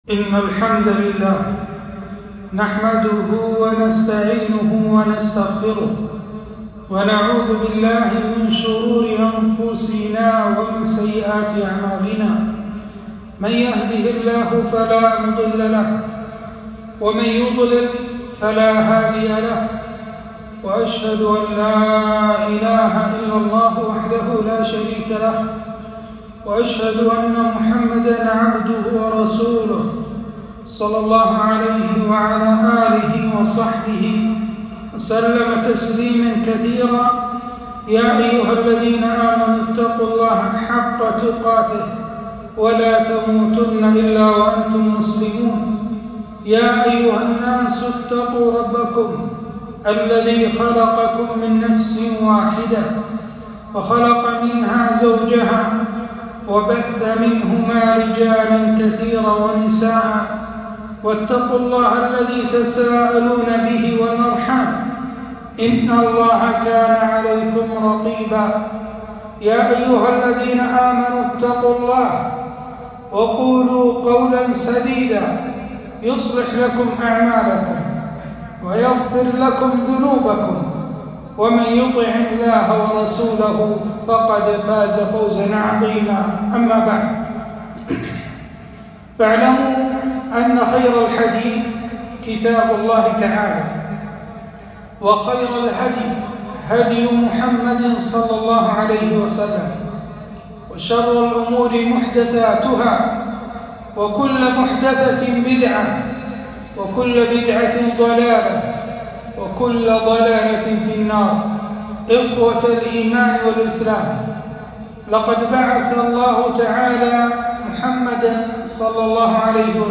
خطبة - أثر الإيمان في حياة الإنسان